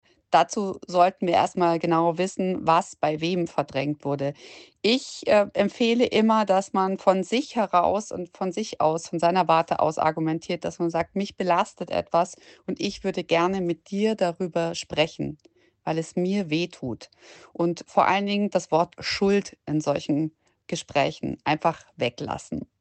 Wir sprechen mit Caro Matzko, Journalistin und TV-Moderatorin.